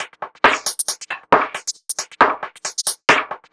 Index of /musicradar/uk-garage-samples/136bpm Lines n Loops/Beats
GA_BeatRingB136-06.wav